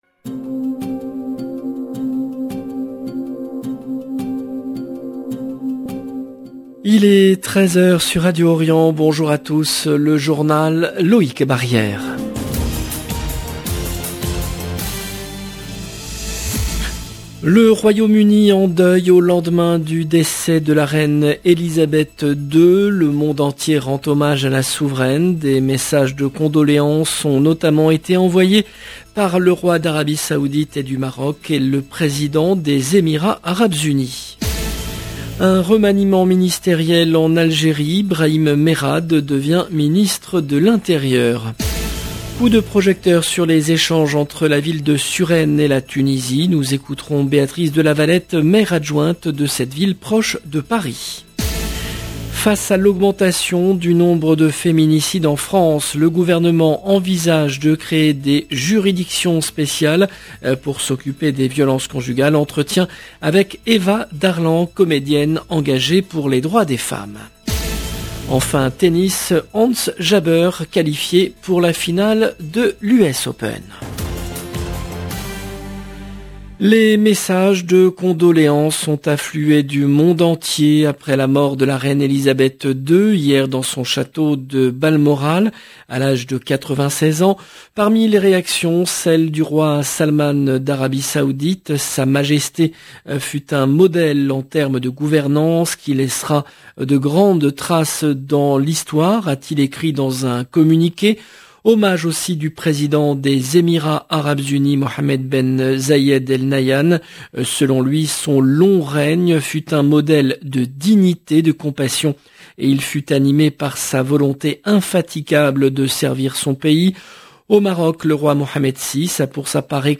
Entretien avec Eva Darlan, comédienne engagée pour les droits des femmes. Enfin Tennis, Ons Jabeur qualifiée pour la finale de l’US Open. 0:00 18 min 21 sec